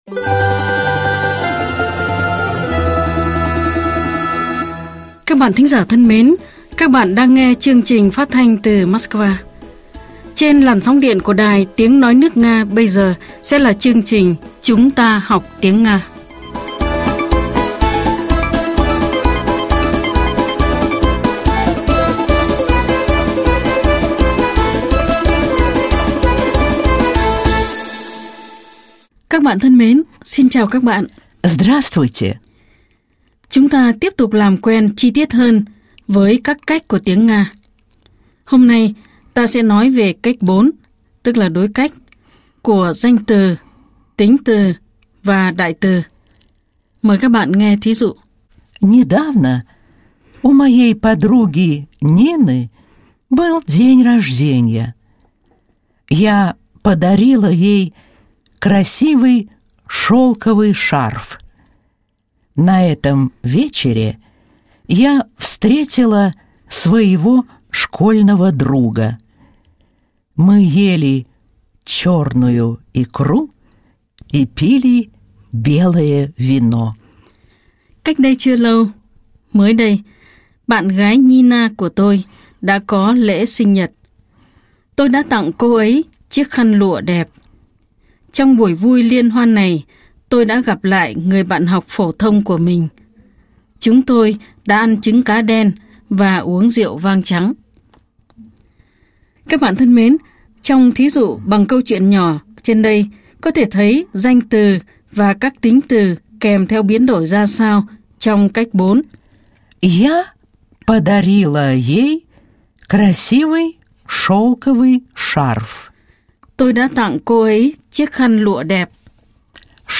Chưa có phản hồi 0 lượt thích Học tiếng Nga qua bài giảng
Nguồn: Chuyên mục “Chúng ta học tiếng Nga” đài phát thanh  Sputnik